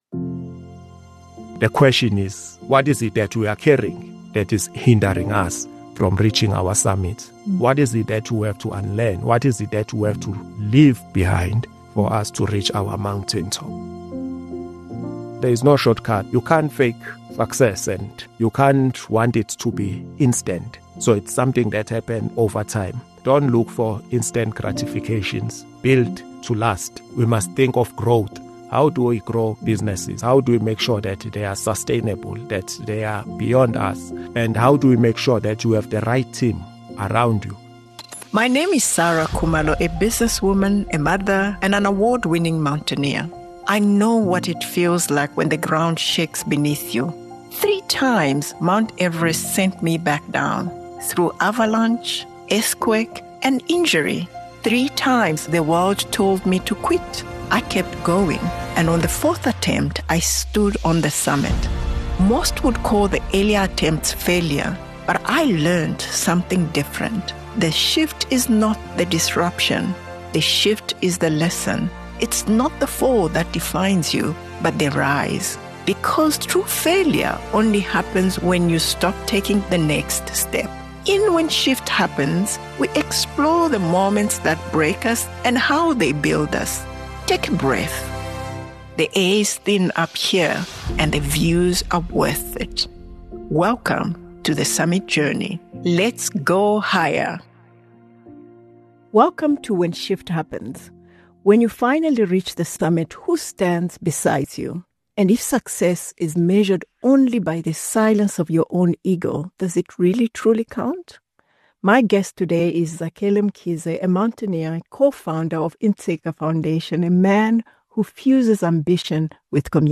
It is a thoughtful conversation about stepping up when the moment calls for it, and about the kind of leadership that grows from character rather than recognition. When Shift Happens explores how people respond when life shifts beneath their feet and what those moments reveal about resilience, purpose and leadership.